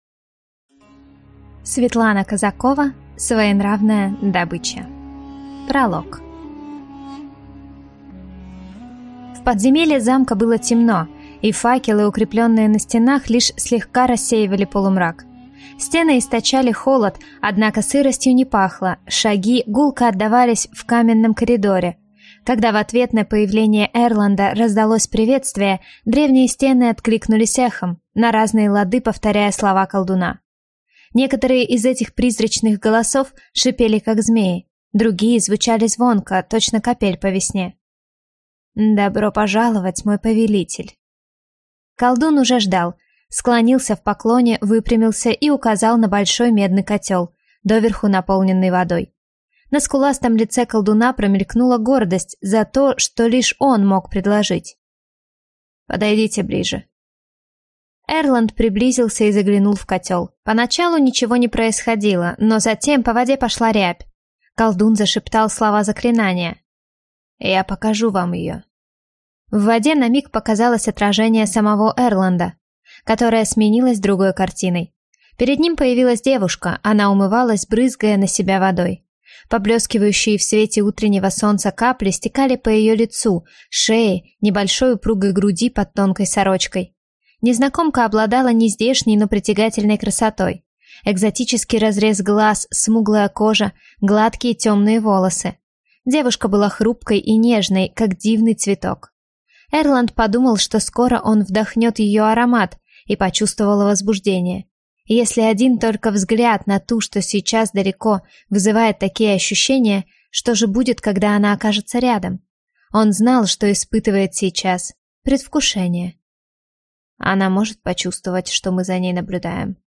Aудиокнига Своенравная добыча